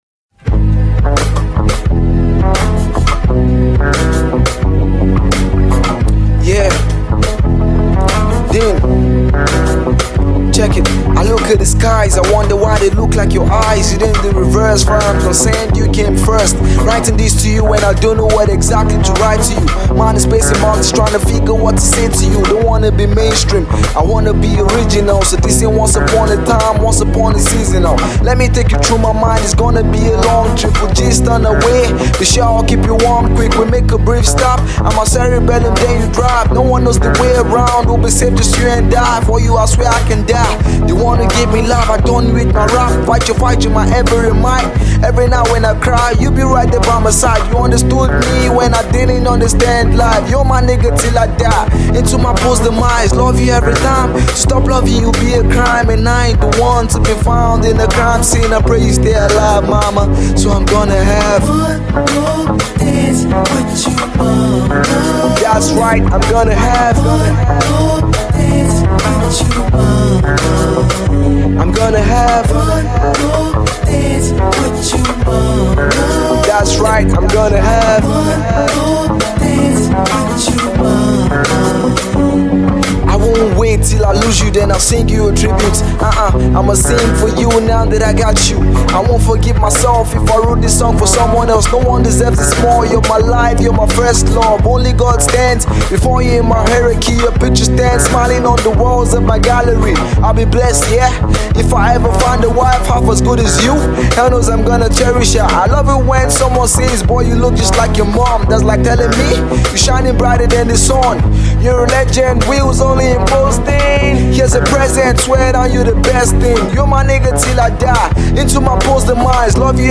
the rapper waxing lyrical